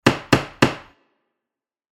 / F｜演出・アニメ・心理 / F-03 ｜ワンポイント1_エフェクティブ
タンタンタン